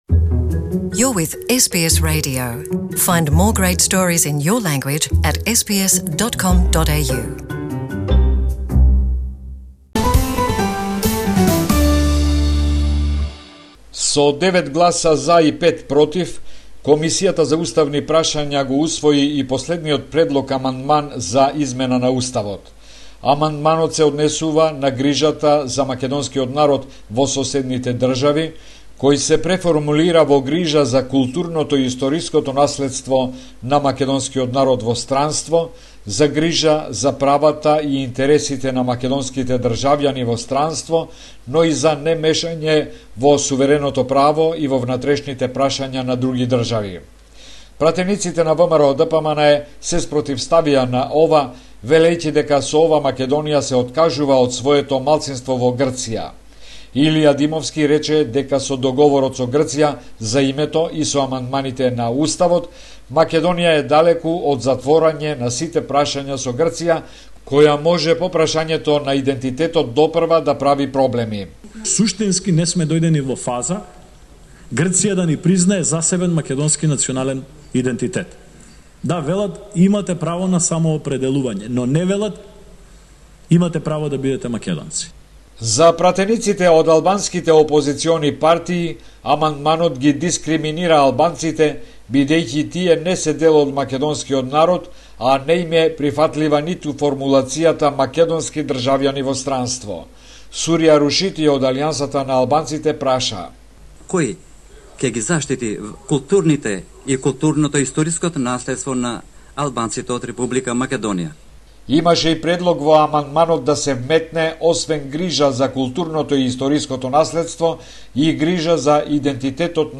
reporting from Macedonia